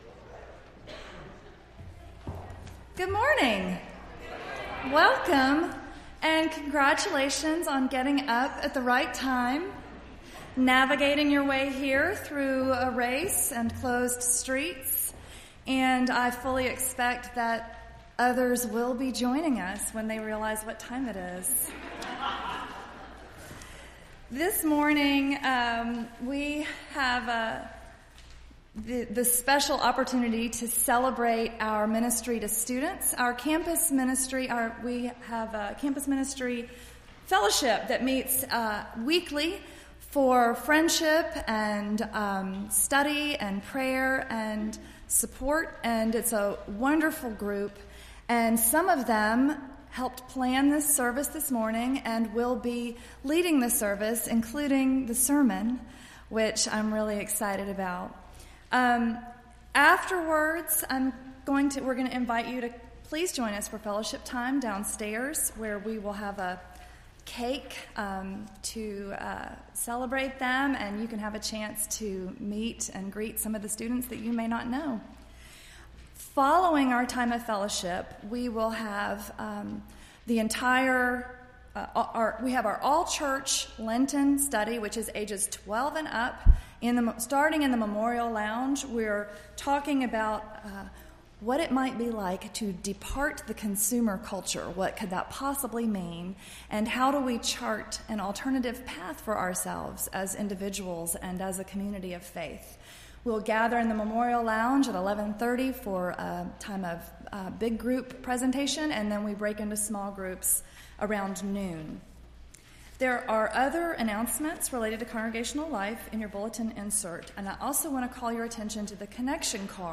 Entire March 12th Service